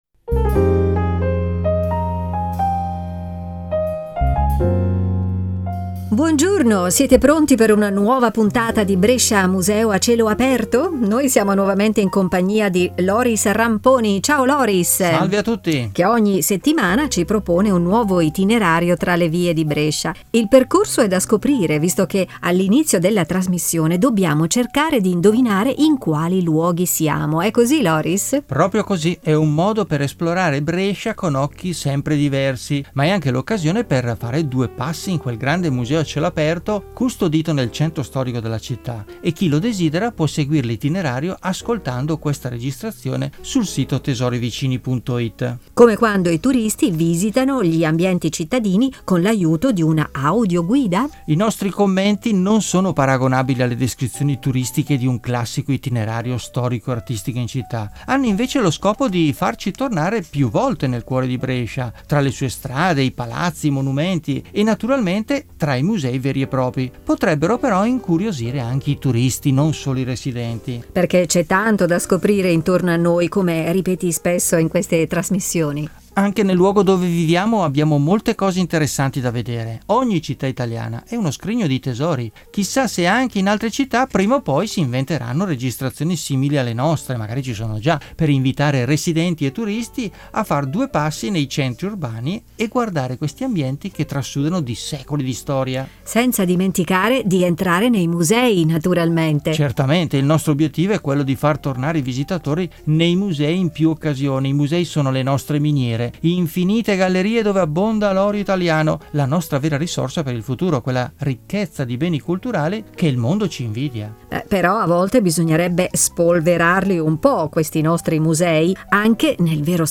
audio-guida e itinerari per passeggiare tra le vie di Brescia